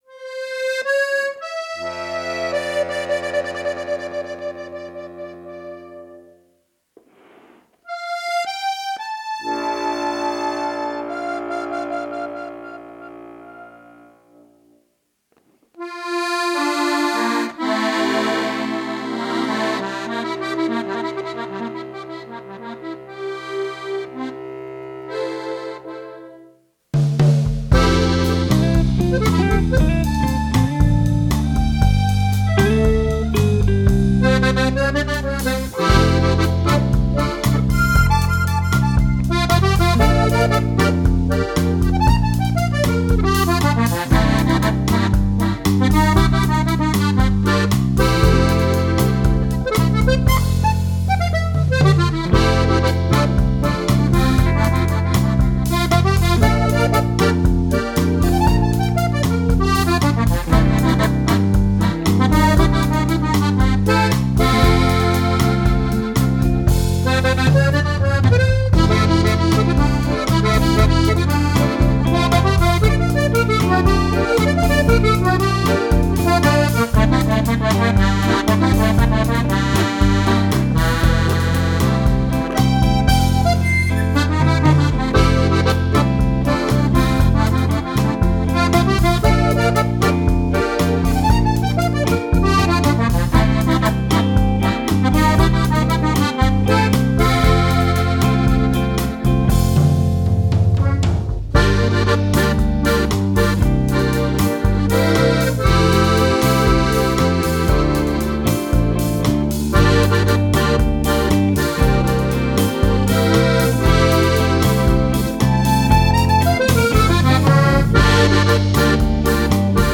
kvartet
v zvrsti jazza, bluesa, latina, funka ipd.